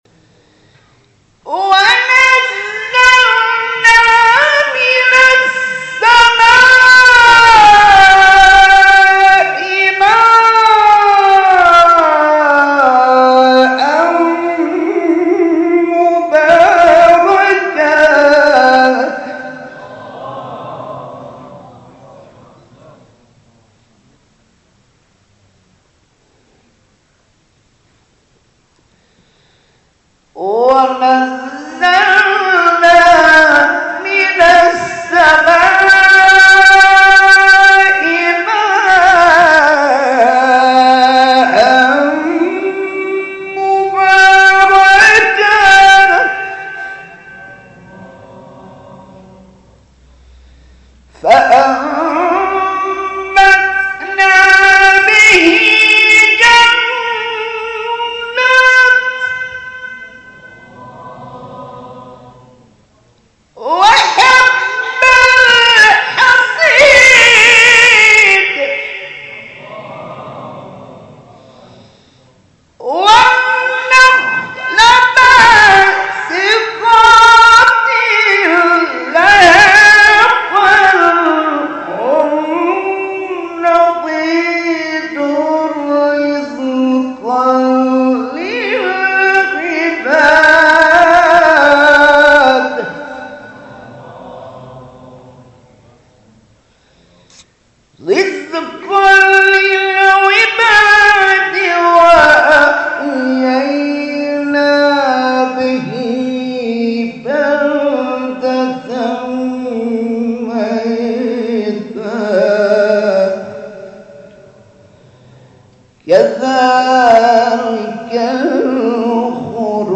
گروه شبکه اجتماعی: جدیدترین مقاطع صوتی از تلاوت قاریان بنام و ممتاز کشور را که به تازگی در شبکه‌های اجتماعی منتشر شده است، می‌شنوید.